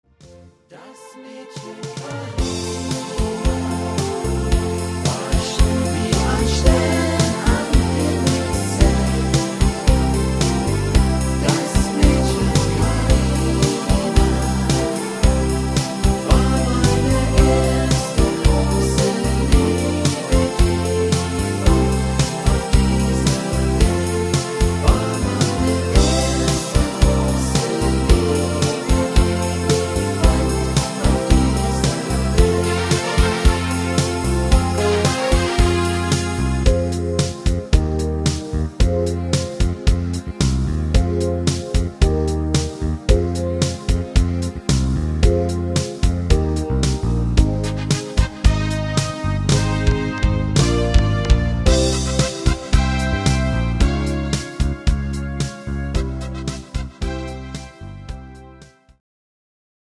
Rhythmus  Medium 8 Beat
Art  Deutsch, Oldies, Schlager 80er